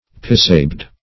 Search Result for " pissabed" : The Collaborative International Dictionary of English v.0.48: Pissabed \Piss"a*bed`\, n. (Bot.) A name locally applied to various wild plants, as dandelion, bluet, oxeye daisy, etc. [1913 Webster]